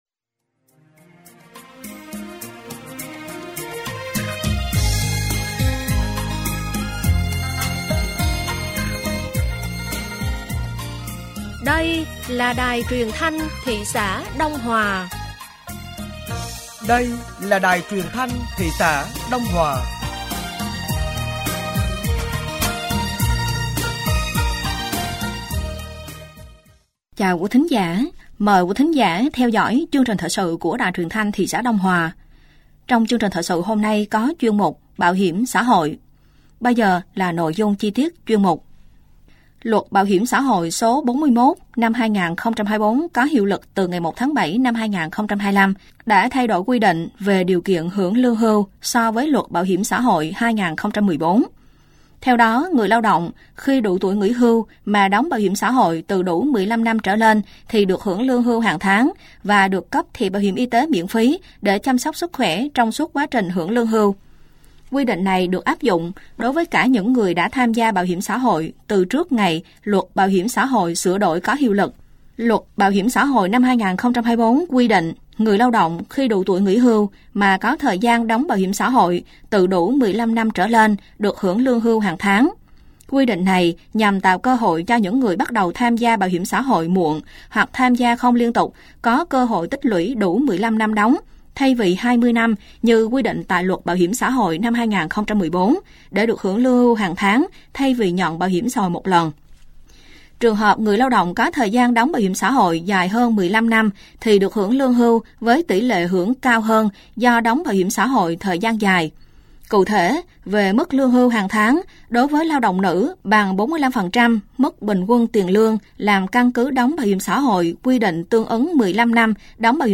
Thời sự tối ngày 26 và sáng ngày 27 tháng 4 năm 2025